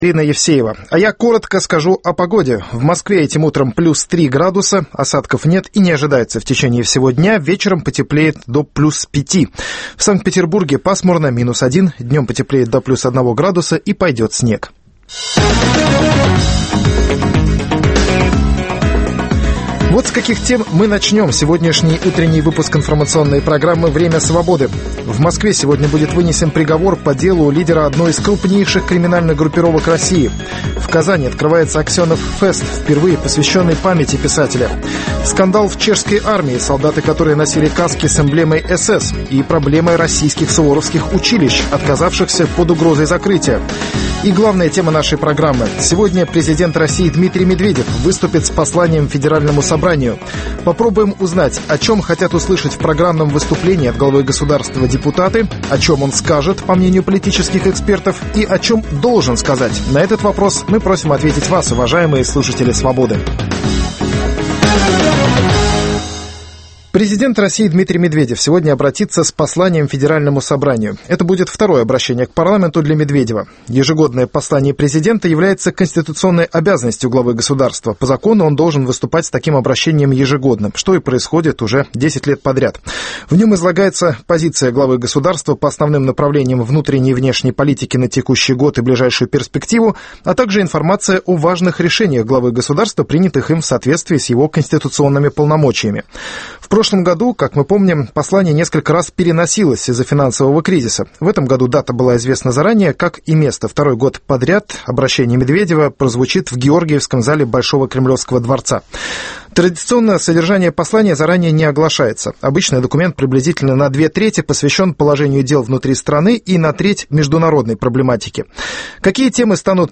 Окончательное подведение итогов дня минувшего, перспективы дня наступившего, обсуждение горячих тем с гостями утреннего эфира.